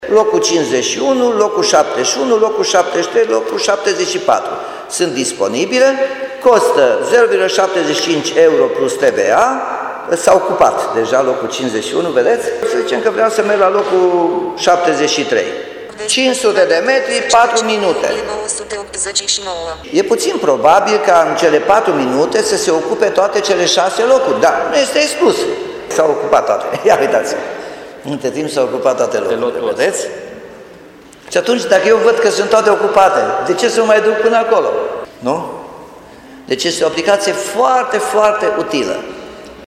Din sala de Consiliu a Primăriei Timișoara, primarul Nicolae Robu a făcut o demonstrație practică.